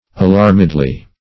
alarmedly - definition of alarmedly - synonyms, pronunciation, spelling from Free Dictionary Search Result for " alarmedly" : The Collaborative International Dictionary of English v.0.48: Alarmedly \A*larm"ed*ly\, adv.